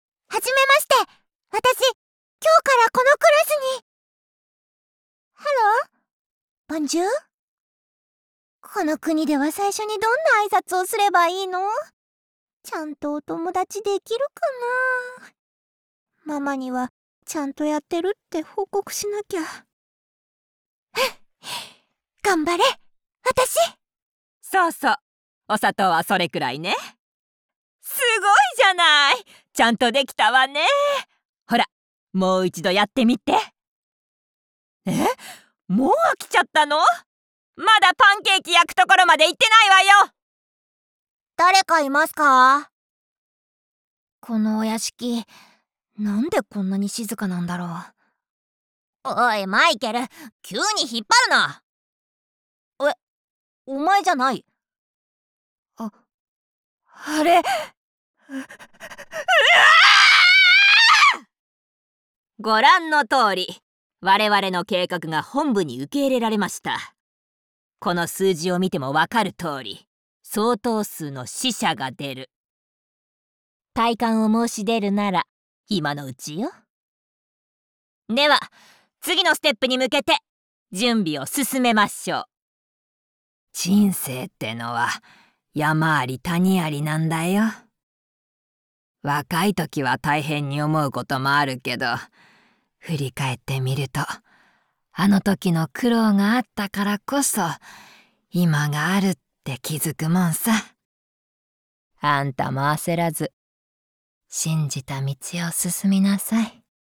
ボイスサンプル(mp3ほか)
外画歌唱